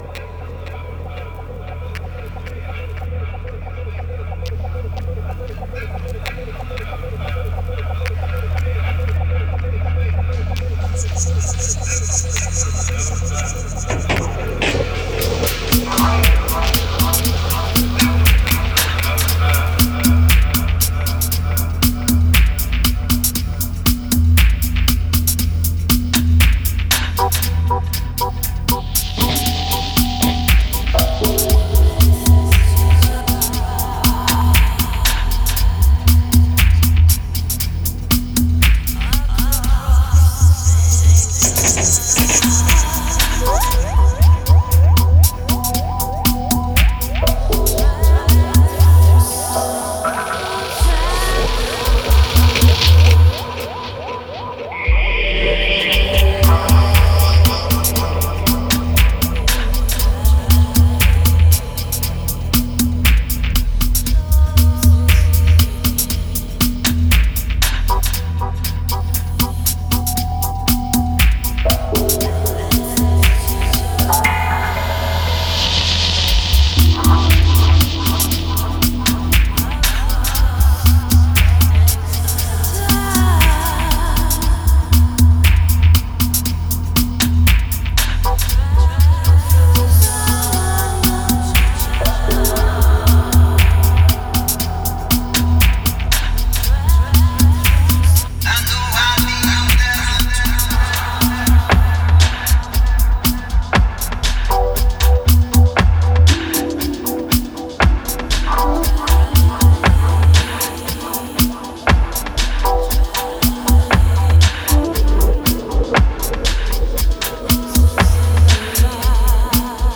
Genre: Dub, Psy-Dub.